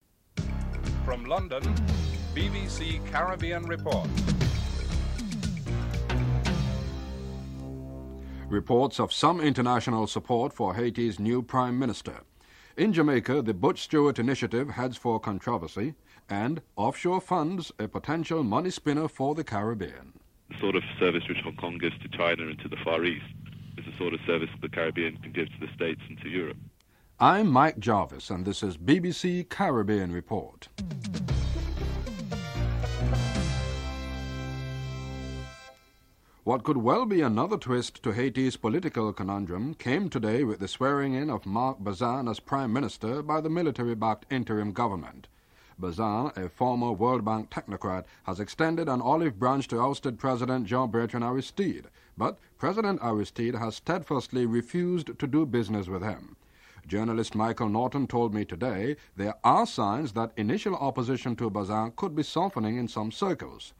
1. Headlines (00:00-00:35)